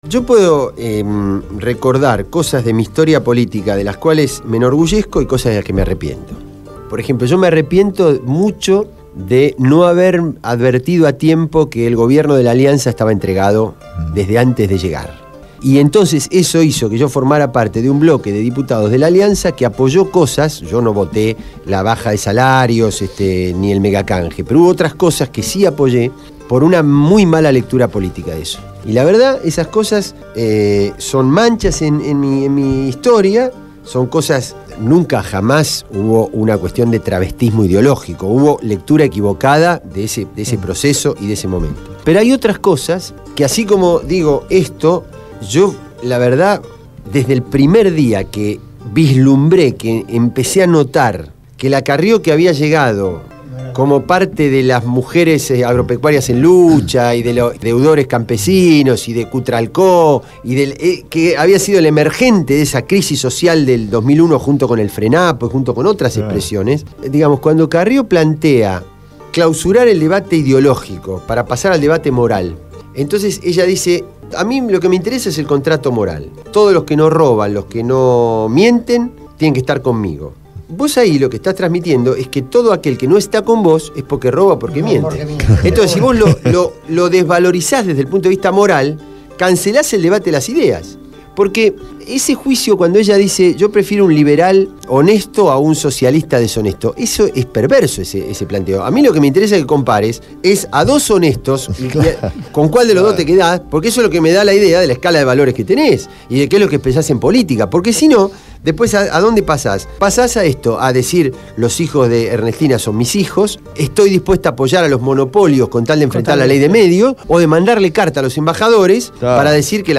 Carlos Raimundi, diputado del bloque Solidaridad e Igualdad (SI) estuvo en el piso de Radio Gráfica durante la emisión del programa «Cambio y Futuro» (Jueves, de 20 a 22 hs).